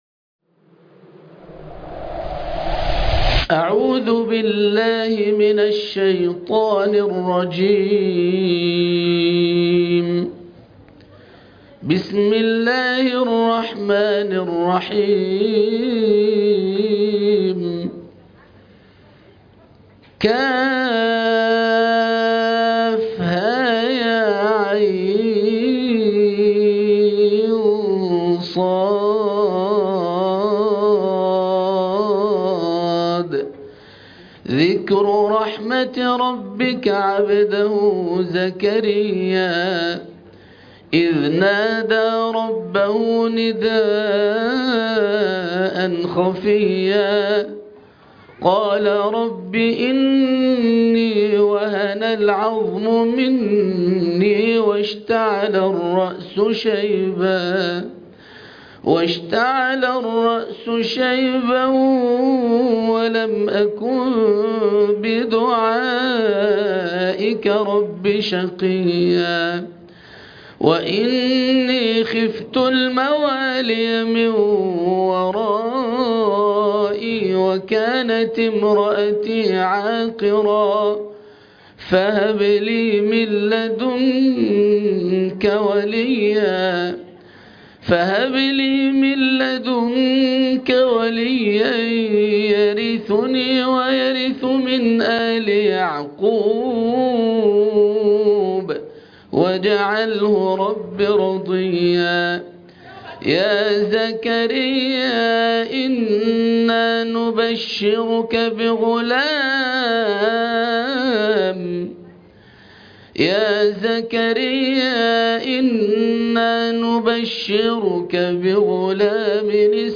تلاوة خاشعة مؤثرة أبكت د .
القرآن الكريم وعلومه     التجويد و أحكام التلاوة وشروح المتون